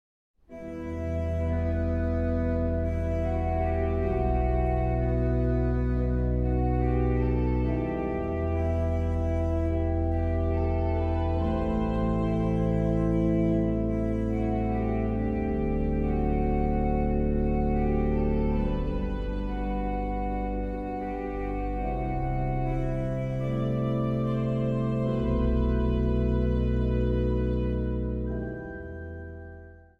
orgel
sopraan
tenor
fluit
hobo
trompet en bugel
hoorn
vleugel.
Zang | Jongerenkoor